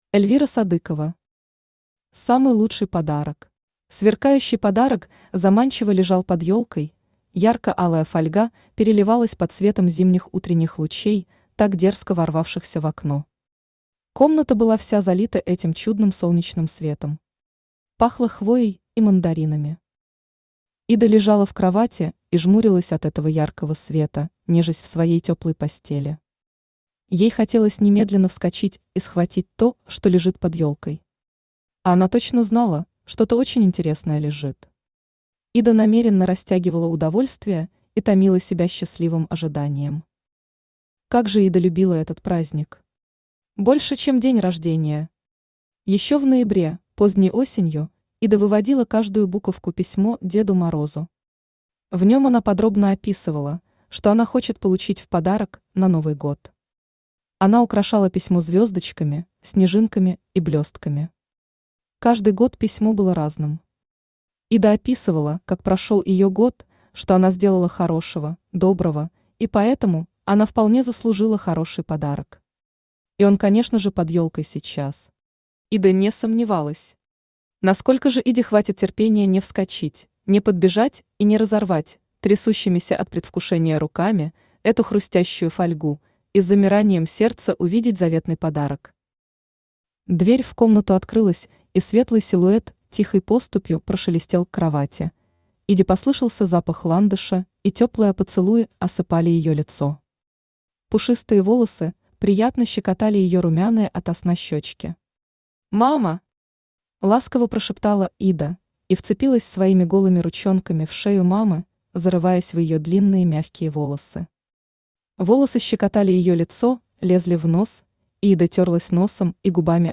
Аудиокнига Самый лучший подарок | Библиотека аудиокниг